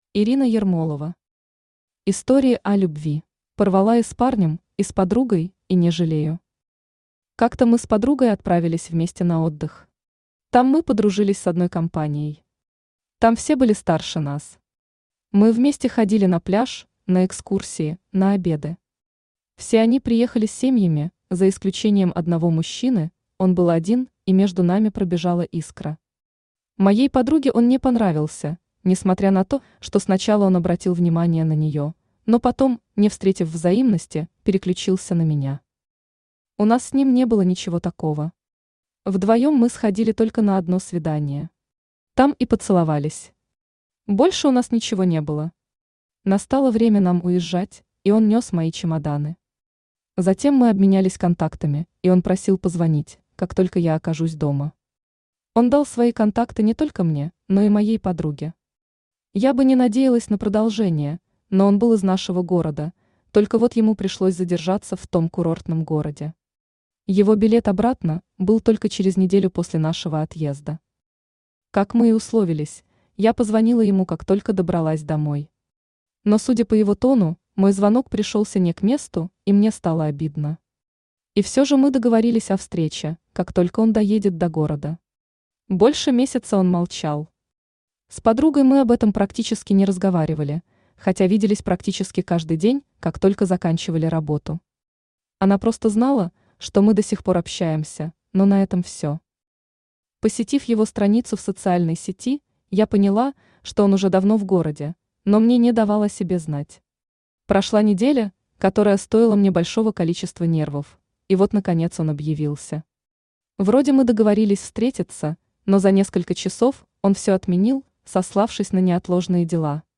Aудиокнига Истории о любви Автор Ирина Ермолова Читает аудиокнигу Авточтец ЛитРес.